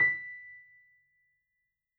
piano_083.wav